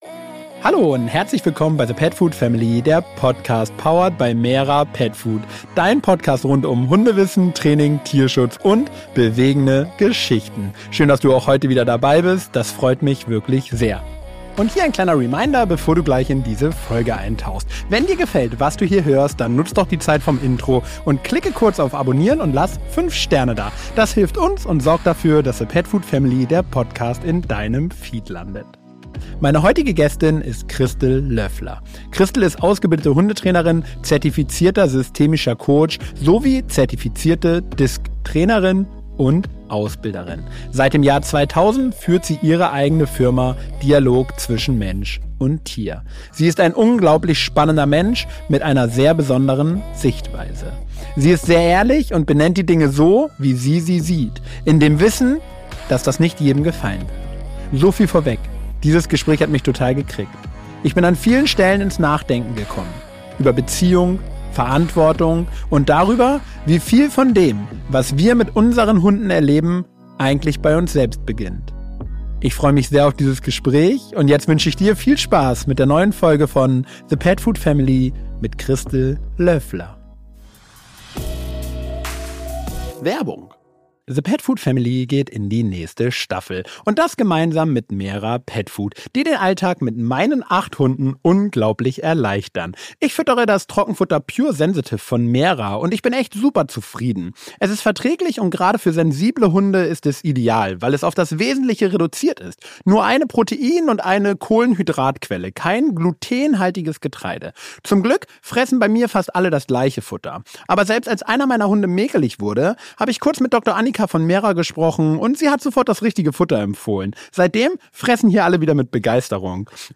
Ein ehrliches Gespräch über Verantwortung, Erwartungen und die Bereitschaft, nicht nur den Hund zu verändern – sondern auch sich selbst.